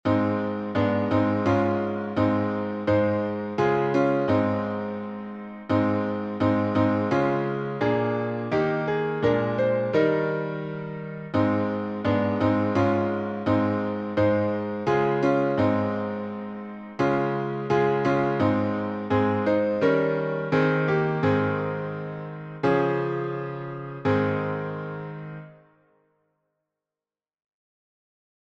Words by Rebecca J. Weston Tune: ONSLOW by Daniel J. Batchellor (1845-1934), 1885 Key signature: A flat